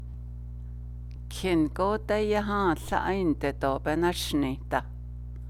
It is inserted for pronunciation purposes, but does not have meaning.